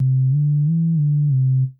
Bass_07.wav